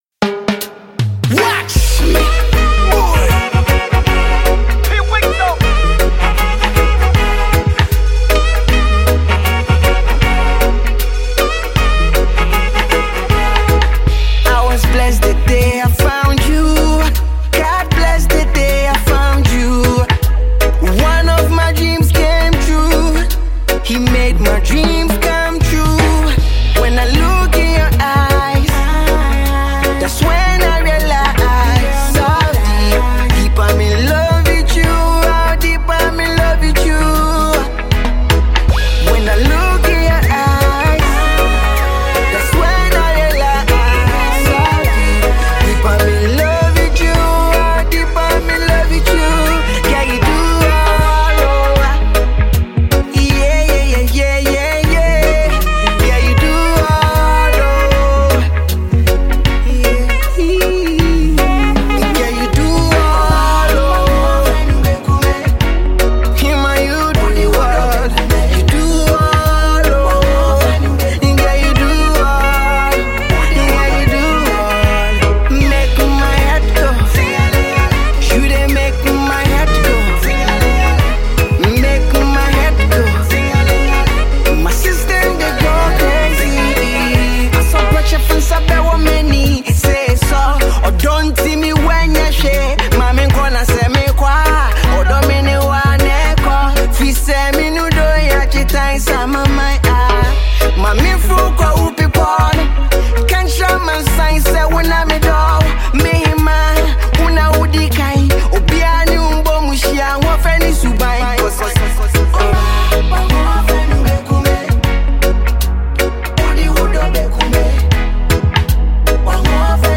He titles this classic reggae masterpiece